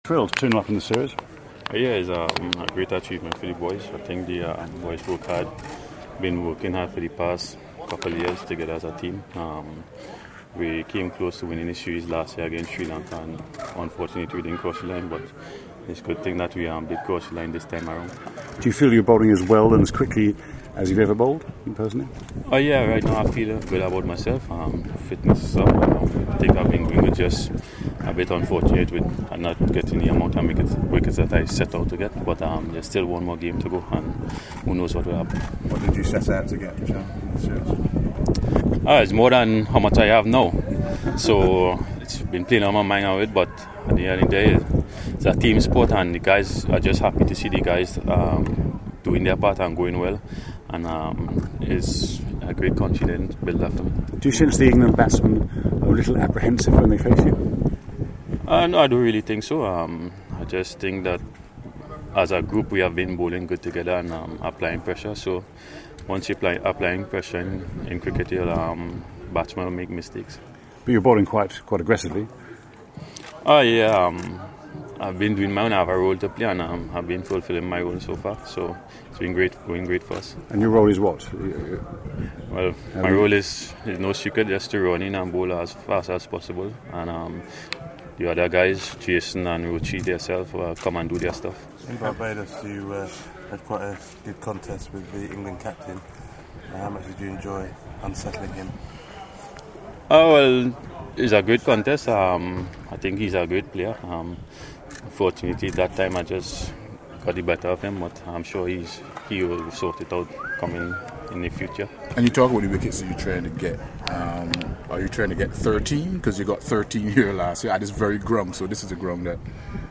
West Indies fast bowler Shannon Gabriel spoke to members of the media as the home side continued preparations for the third and final Test of the Wisden Trophy Series.